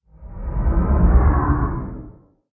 elder_idle1.ogg